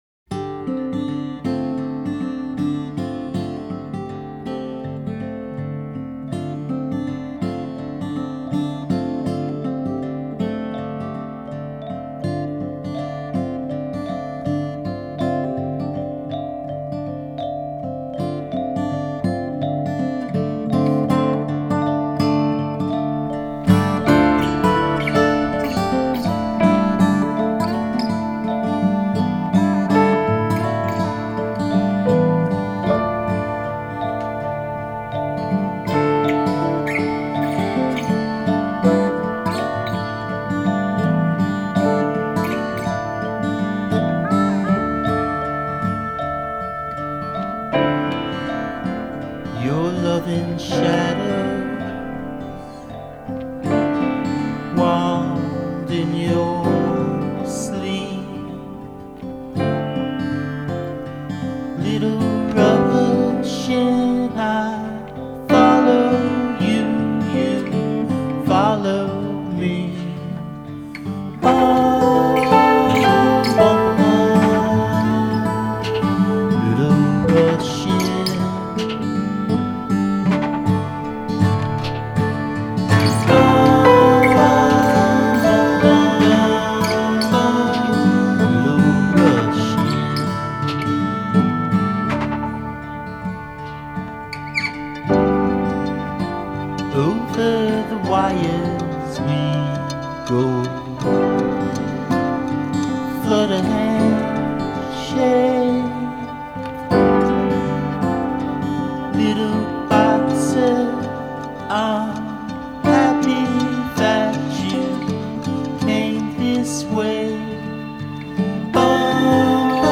Chicago-based trio